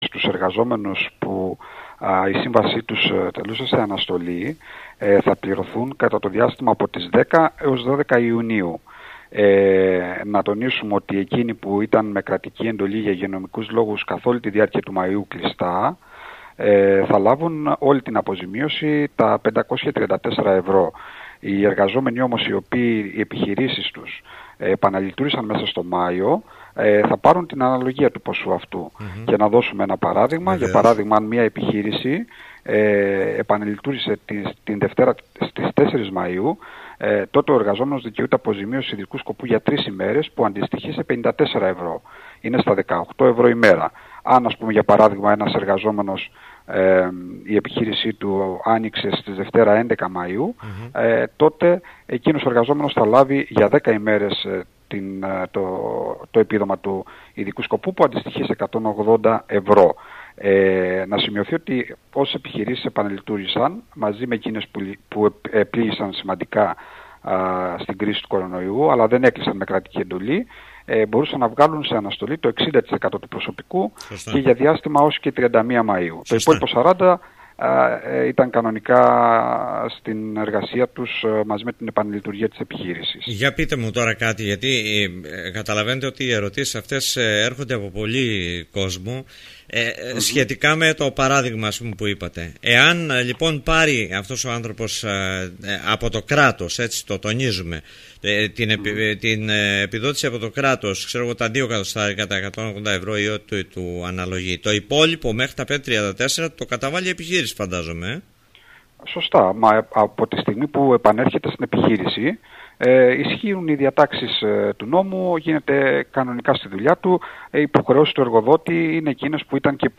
μίλησε στον Politica 89.8